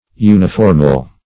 uniformal - definition of uniformal - synonyms, pronunciation, spelling from Free Dictionary Search Result for " uniformal" : The Collaborative International Dictionary of English v.0.48: Uniformal \U`ni*form"al\, a. Uniform.